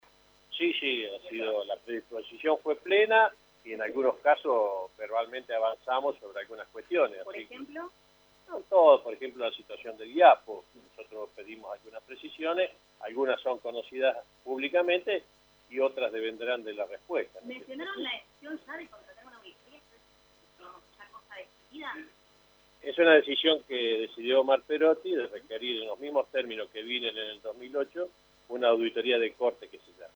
Michlig , que habló con la prensa al término de la reunión, confirmó que Perotti les transmitió la decisión de «requerir una auditoría que se llama de corte» a la administración saliente.